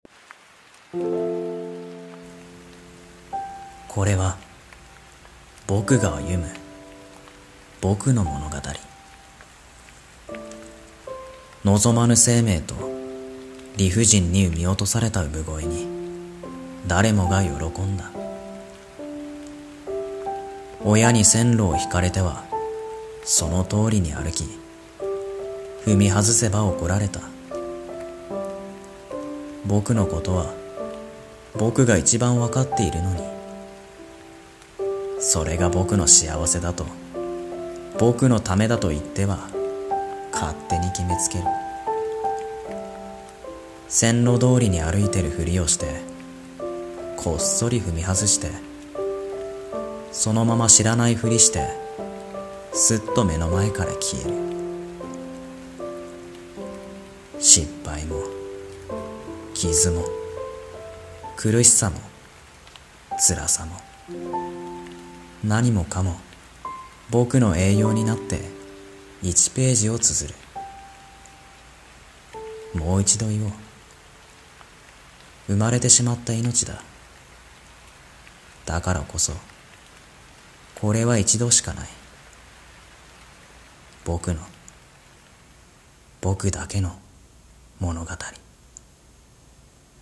【声劇】僕が綴る物語